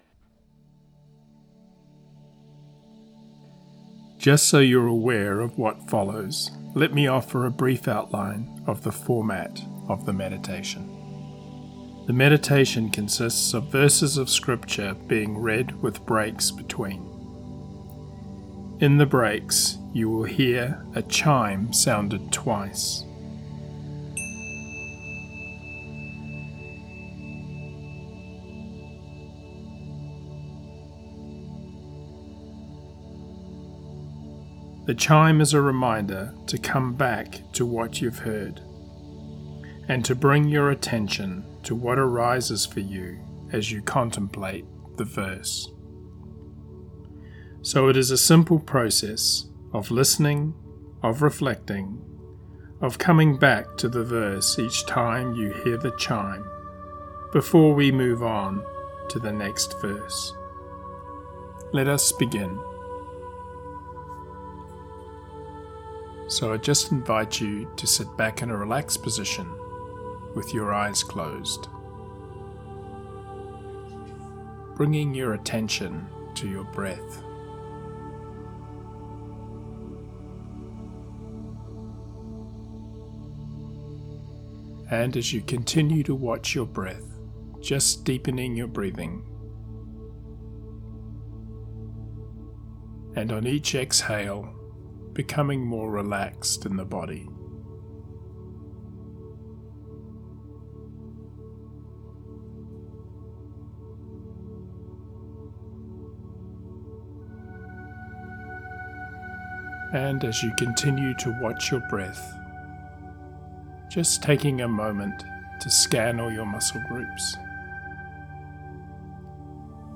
Genre: Guided Meditation.
Ps29Meditation.mp3